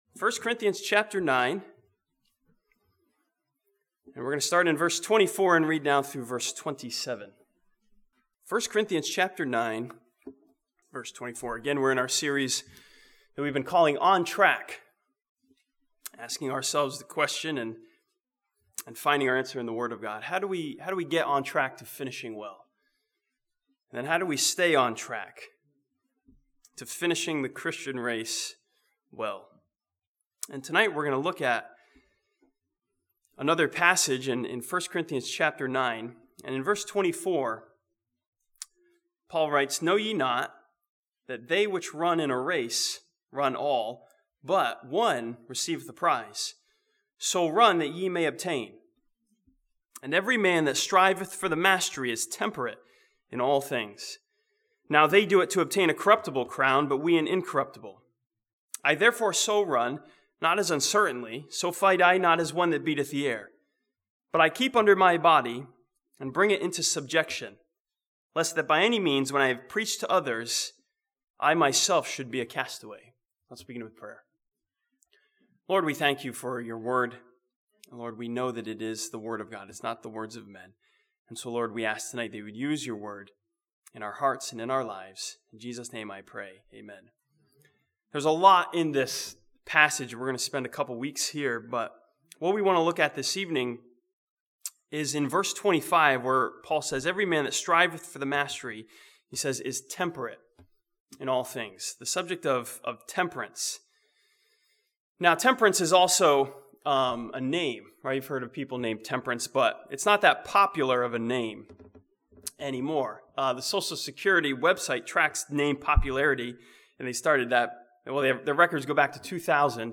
This sermon from 1 Corinthians chapter 9 challenges the believer to view temperance as important for staying on track.